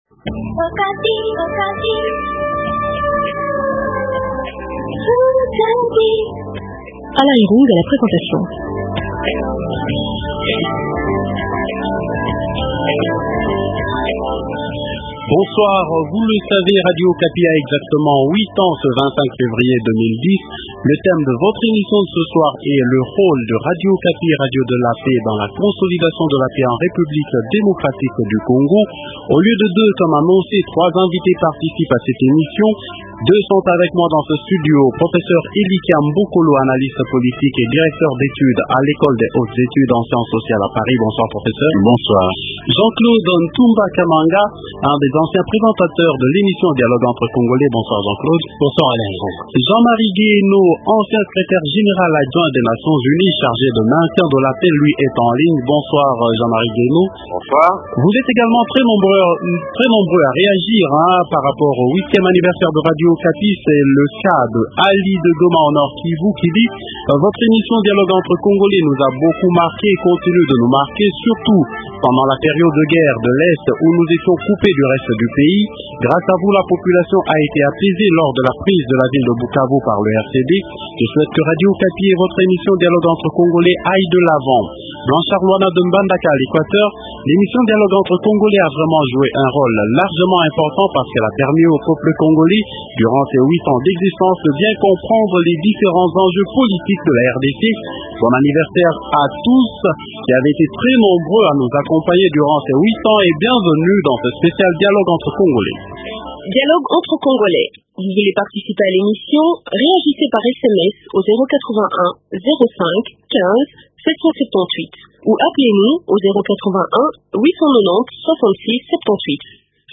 Elikia Mbokolo, Analyste politique et directeur d'études à l'école des hautes études en sciences sociales à Paris